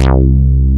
ACIDBAS3.wav